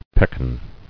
[pek·an]